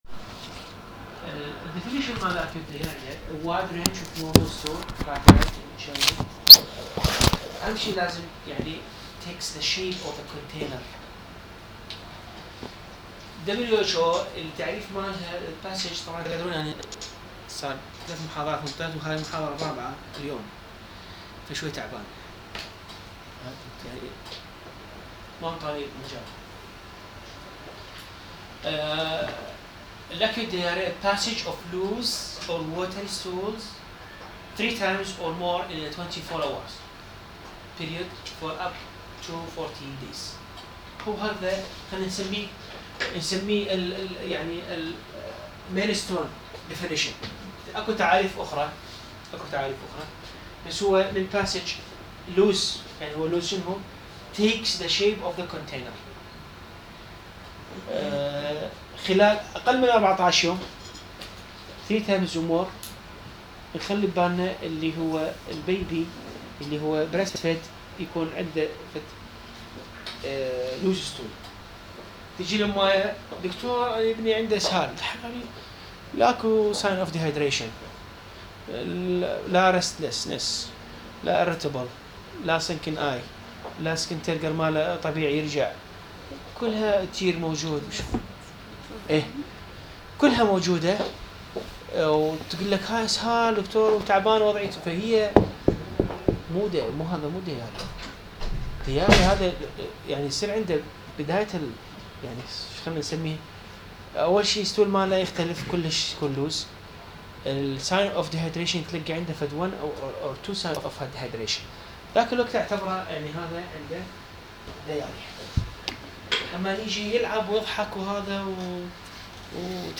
اطفال - عملي > محاضرة رقم 1 بتاريخ 2016-10-01